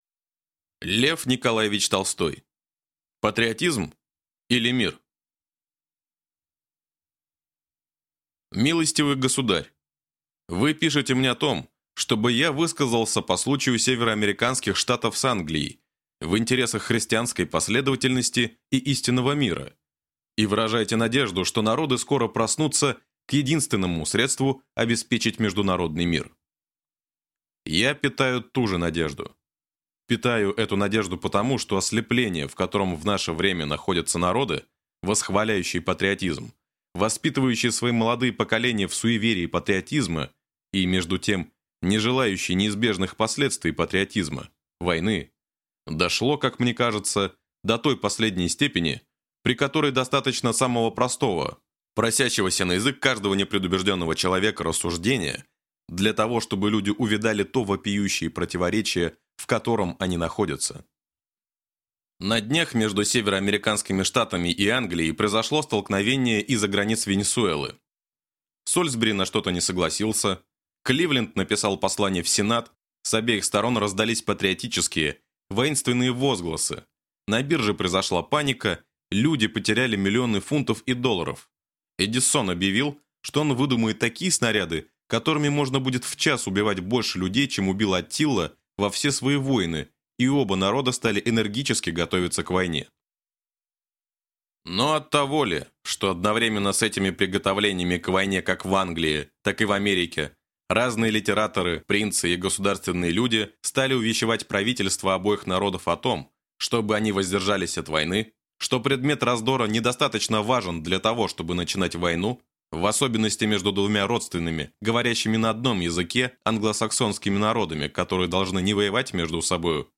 Аудиокнига Патриотизм или Мир?